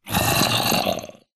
zombie2.ogg